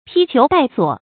披裘帶索 注音： ㄆㄧ ㄑㄧㄡˊ ㄉㄞˋ ㄙㄨㄛˇ 讀音讀法： 意思解釋： 形容衣著粗陋。